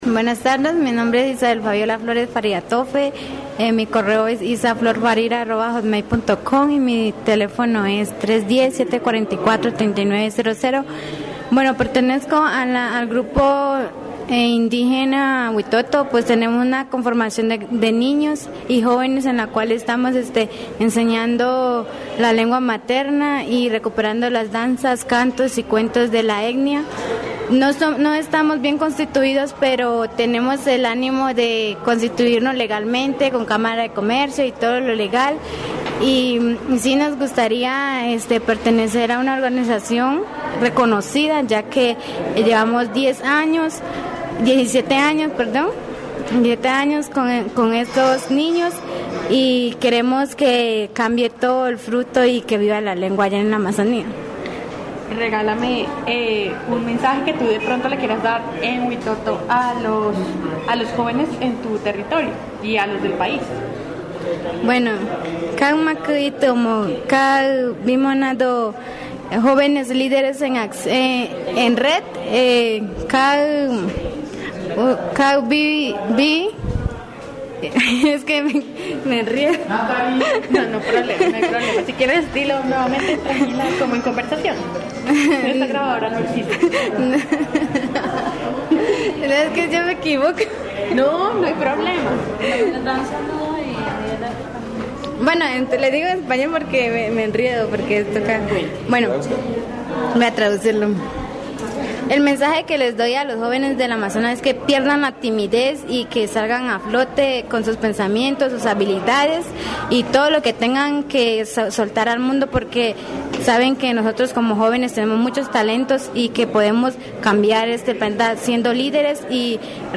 Programas de radio